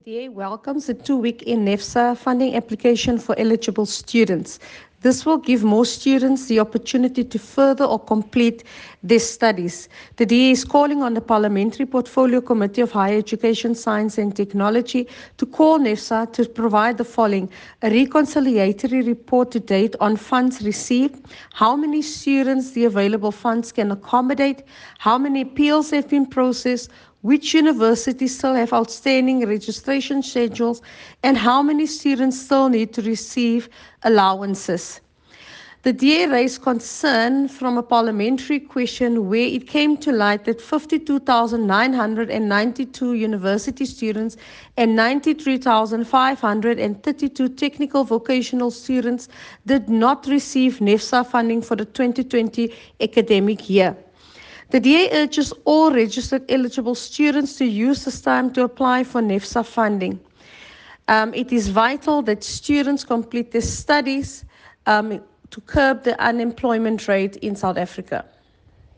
soundbite by Chantel King MP.